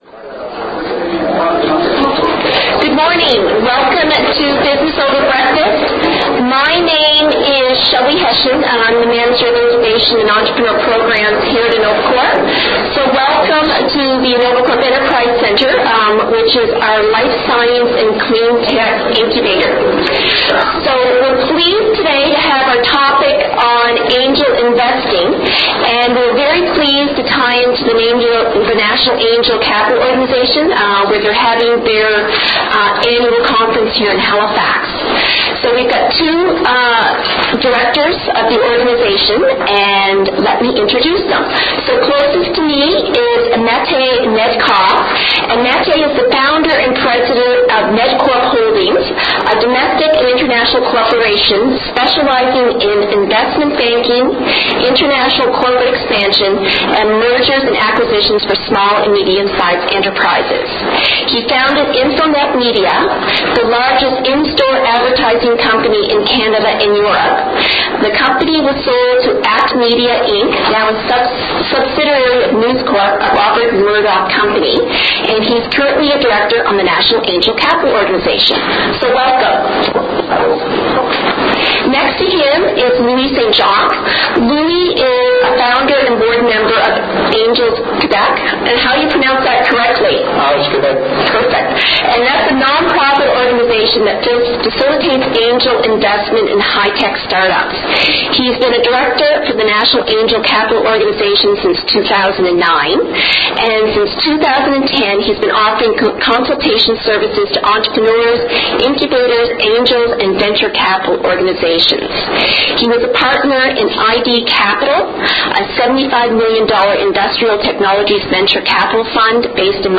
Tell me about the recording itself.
CLICK HERE TO LISTEN TO A PODCAST OF THE EVENT BELOW THAT TOOK PLACE ON OCTOBER 26, 2012, AT THE INNOVACORP ENTERPRISE CENTRE.